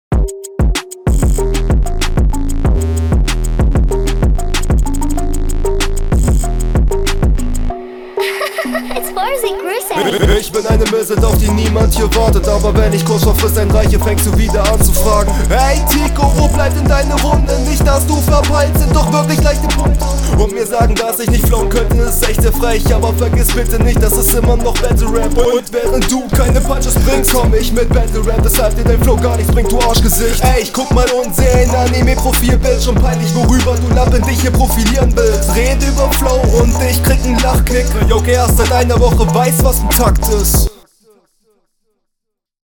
Text: startest mit stuttereffekt und erste Line im Grunde ein solider Konter, aber "fängst du …
Flow: Flowlich ist das ganze schon ein gutes Stück schlechter, vorallem gegen Anfang wirkt das …